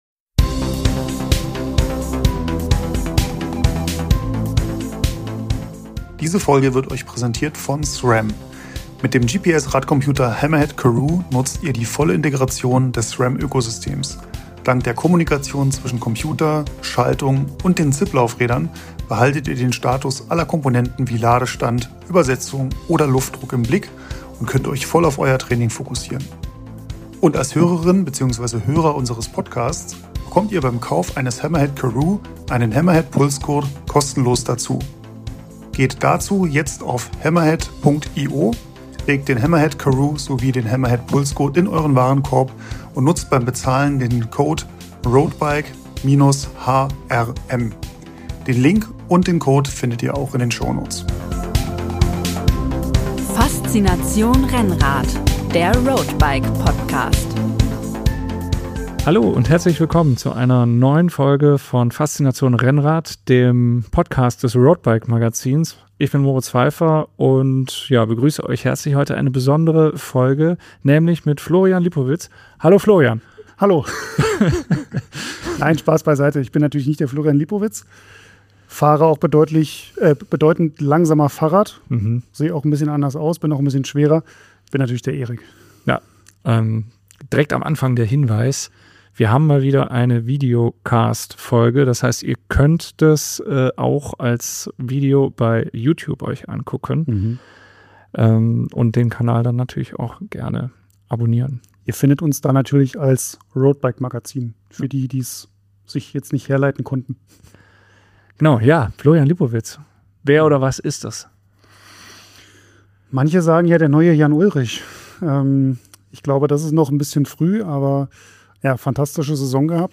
Unser erster Podcast vor Publikum! Was macht Tour-Etappensieger Simon Geschke sechs Monate nach seinem Karriereende? Wie führt man eine Gruppe, wie gibt man wertschätzend Tipps?